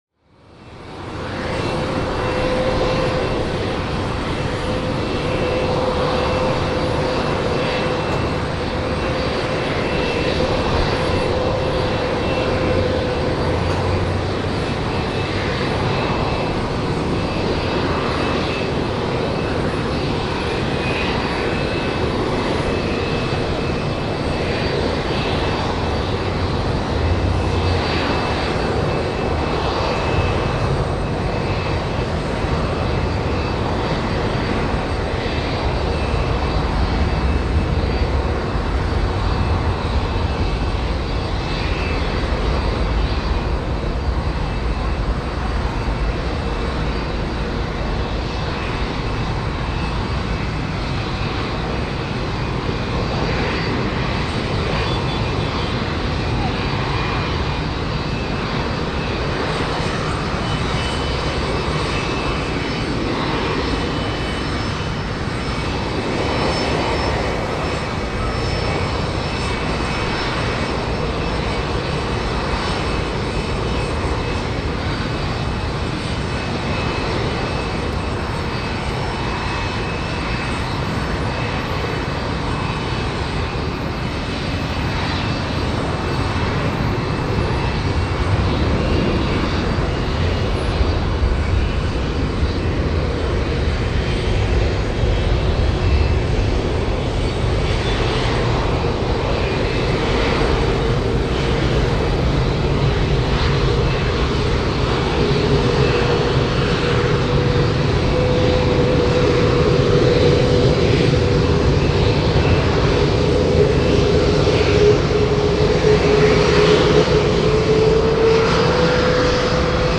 Окунитесь в атмосферу аэропорта с коллекцией звуков объявлений и фоновых шумов.
Звуки улицы